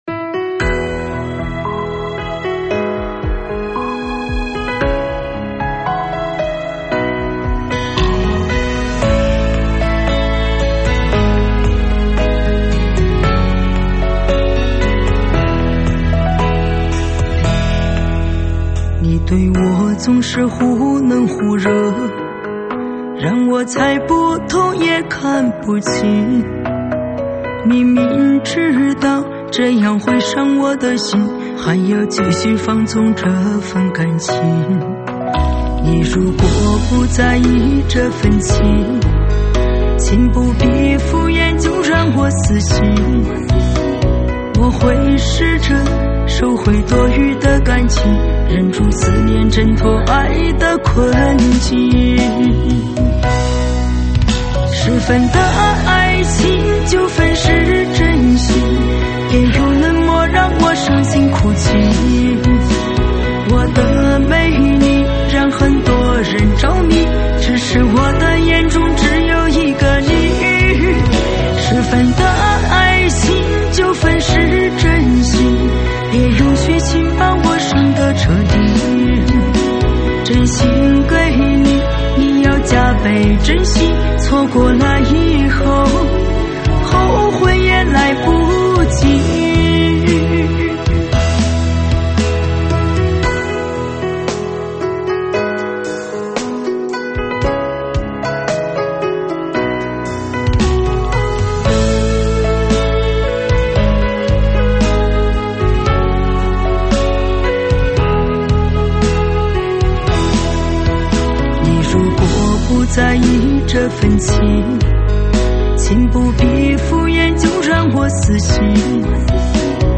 舞曲类别：车载大碟